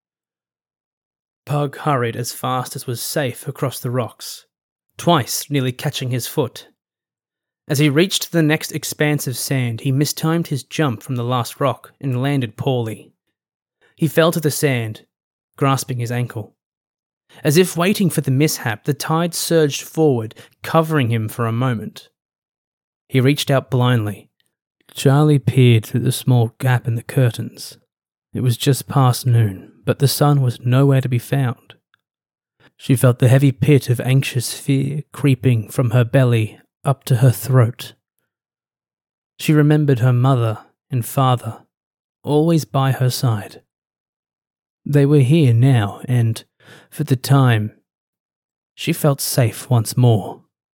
Male
English (Australian)
I can use my voice for a chipper medium to high pitched character full of energy, to a rich an intimate style more suited for narration.
Audiobooks
Excerpt From Audio Books
All our voice actors have professional broadcast quality recording studios.